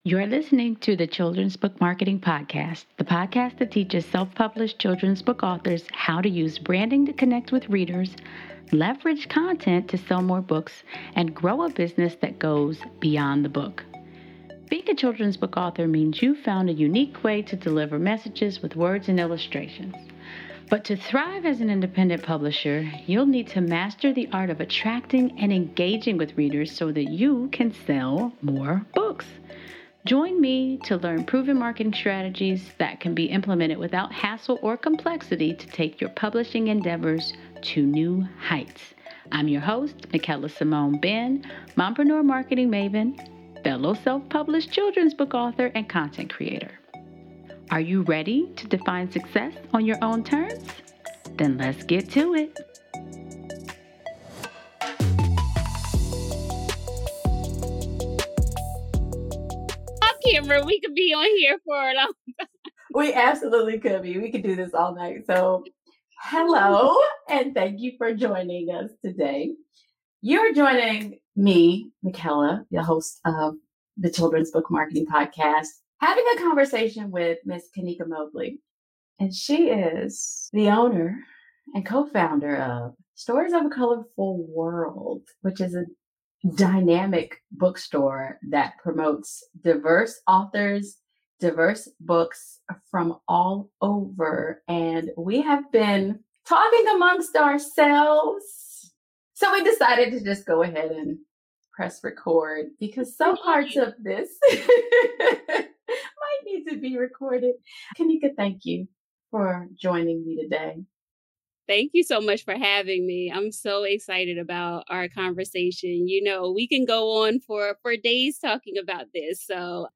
I have a conversation